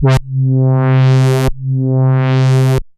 Roland A C3.wav